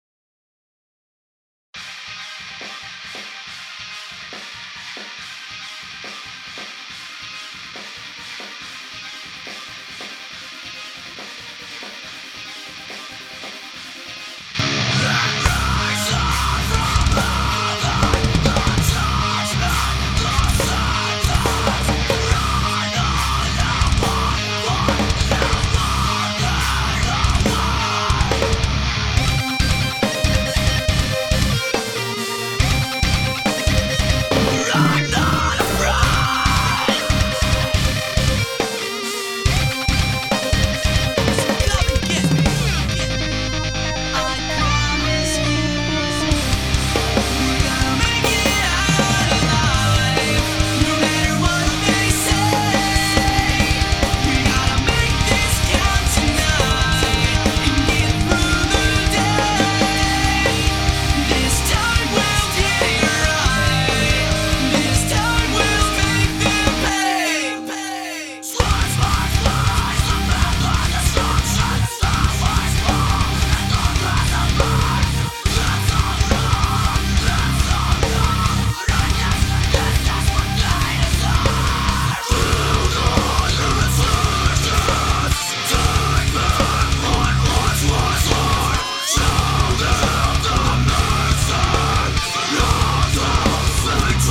J'ai voulu avoir ce son avec beaucoup d'imprécisions dans le haut medium et l'impression d'avoir 1 tonne de gain :
->SM57 d'office et clean boost en entrée de l'ampli.
Sur la basse un bon boost sur le bas et une belle compression pour avoir un bas "tendu" et toujours sensiblement identique quelques soit les passages du morceaux qui sont très différents.
Certains noterons un léger déphasage sur les machines.